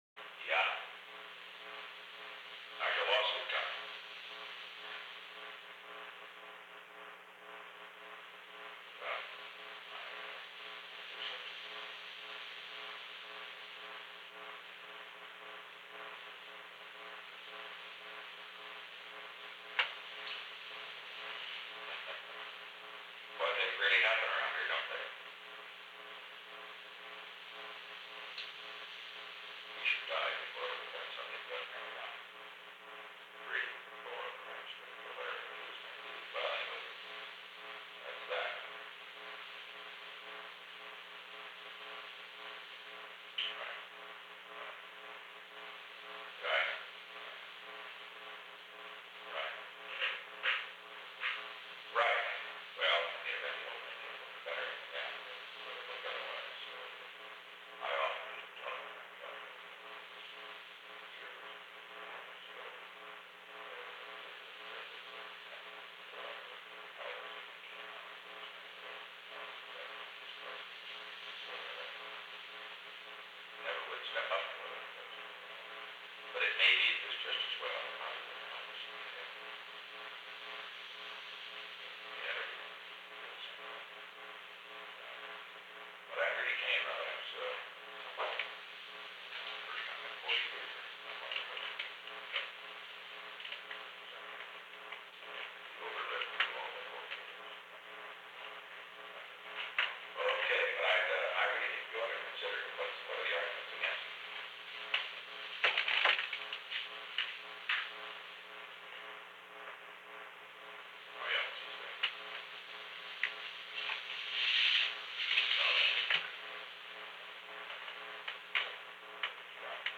Conversation: 402-016
Recording Device: Old Executive Office Building
On January 22, 1973, President Richard M. Nixon and Charles W. Colson met in the President's office in the Old Executive Office Building at an unknown time between 8:47 pm and 8:50 pm. The Old Executive Office Building taping system captured this recording, which is known as Conversation 402-016 of the White House Tapes.
The President talked with Charles W. Colson.